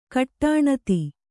♪ kaṭṭāṇati